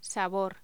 Locución: Sabor
voz
Sonidos: Voz humana